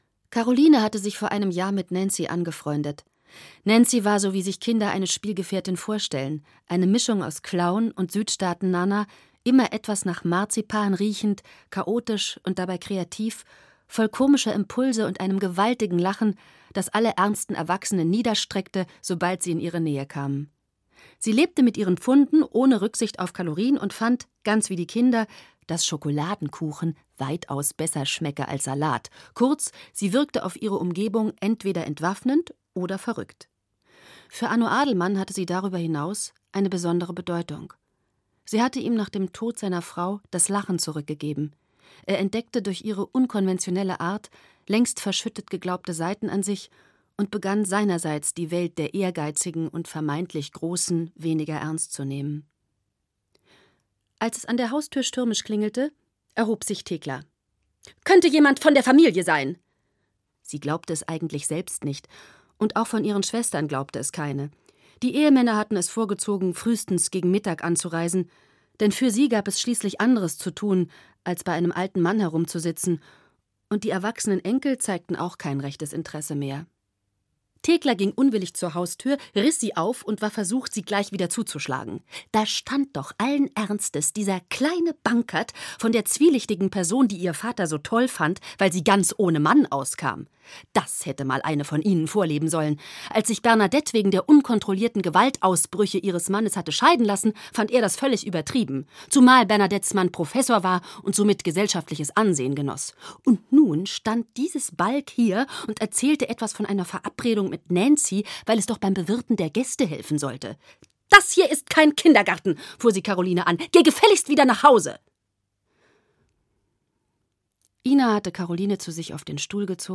Hörbuch Die Meute der Erben, Gaby Hauptmann.